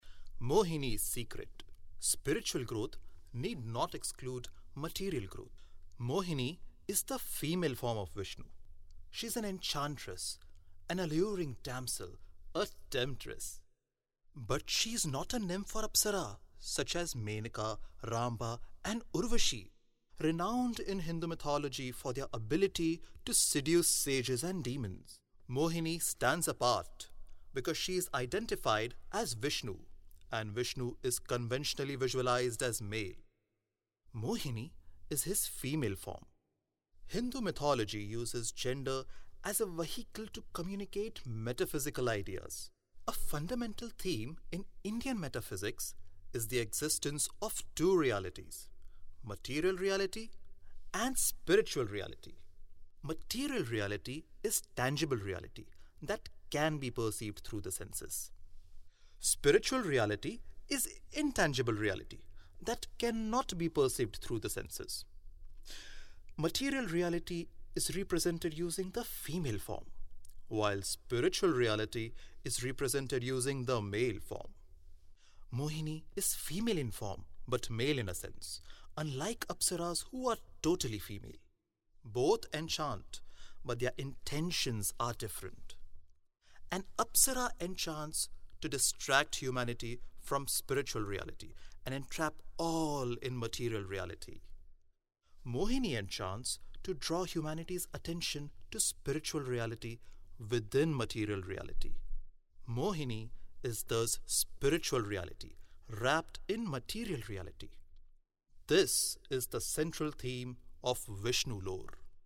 Has a young voice which suits narrative style.
Sprechprobe: Sonstiges (Muttersprache):